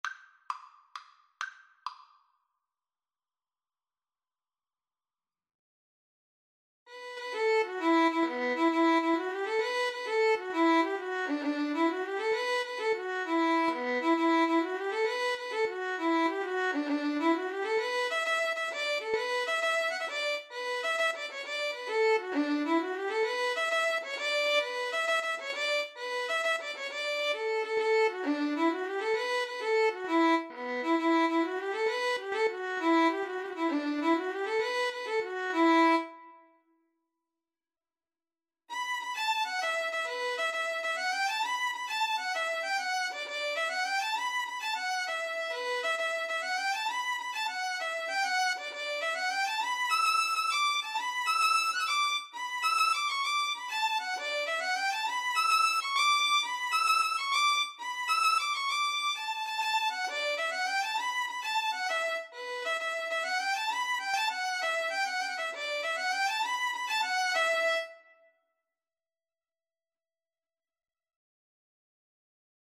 E minor (Sounding Pitch) (View more E minor Music for Violin-Guitar Duet )
rocky_road_VNGT_kar2.mp3